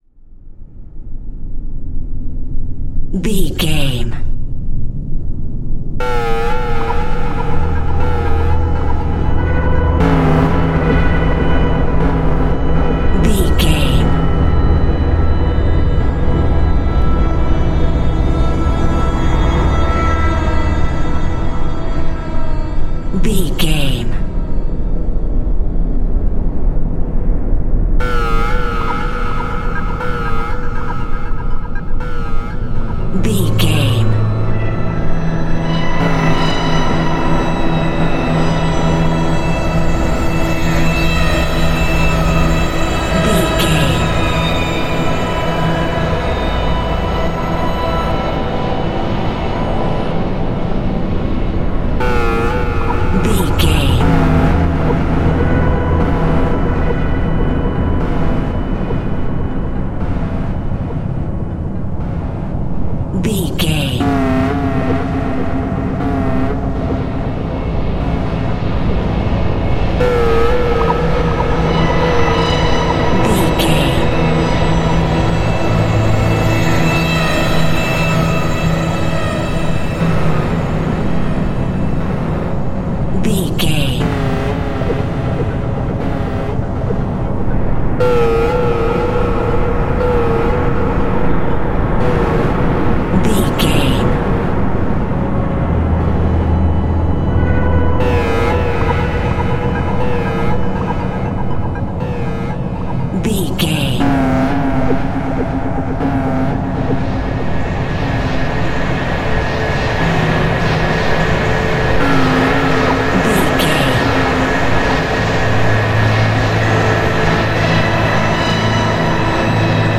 Horror Background Ambience.
Atonal
tension
ominous
dark
haunting
eerie
synth
pads